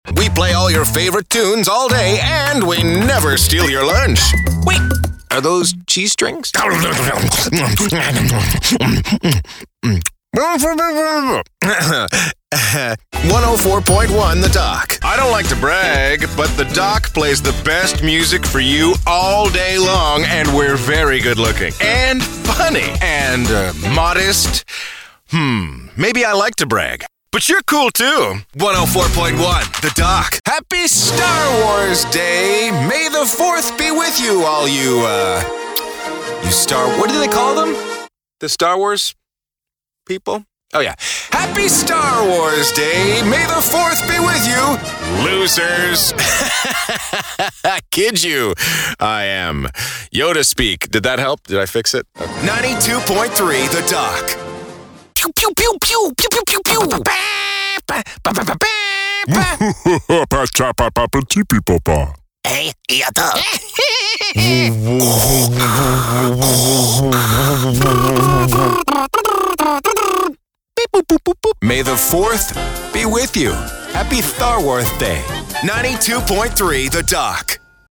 Muestras de voz nativa
Imágenes de radio